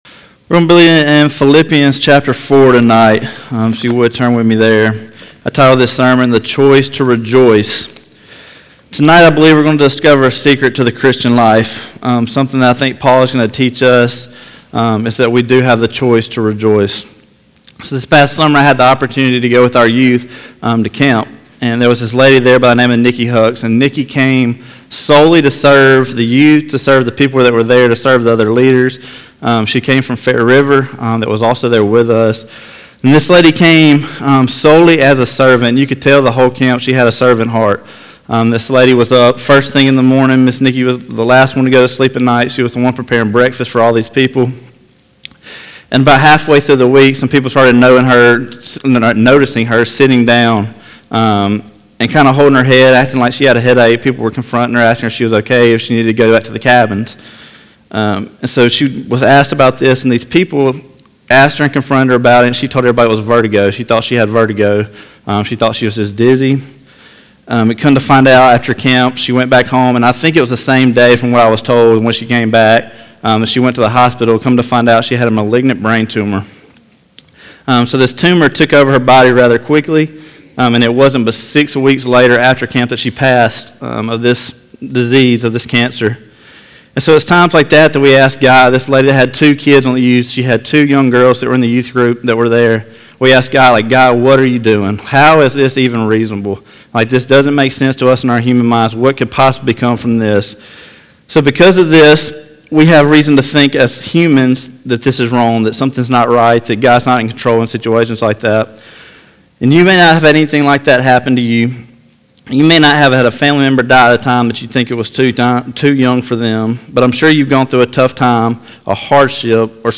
11/01/2020 – Sunday Evening Service